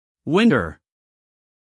winter-optional-us-male.mp3